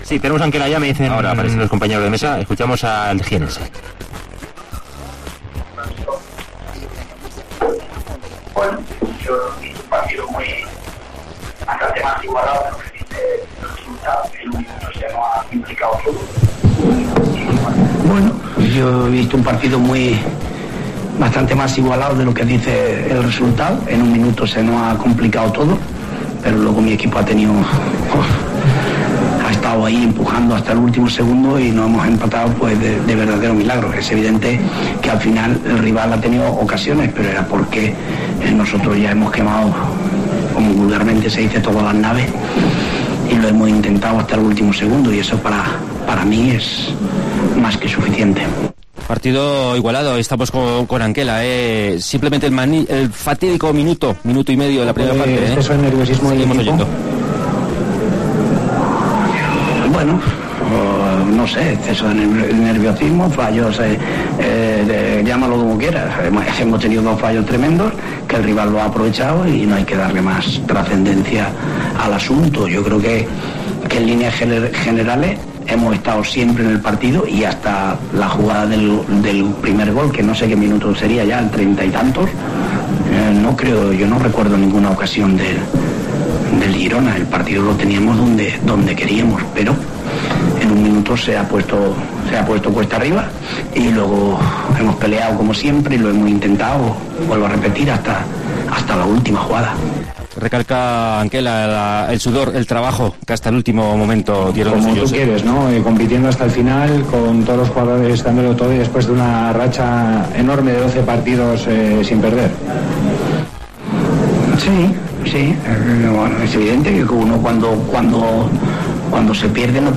Declaraciones de Anquela en Gerona